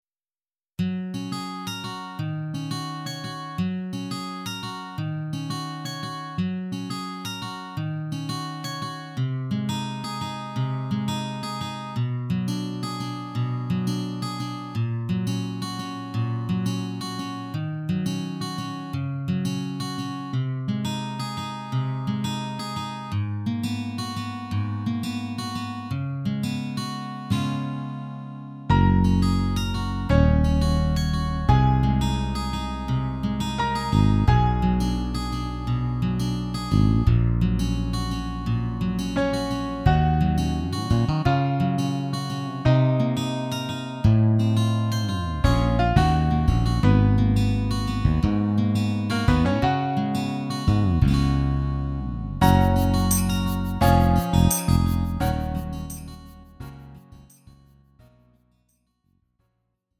음정 -1키 3:41
장르 가요 구분 Lite MR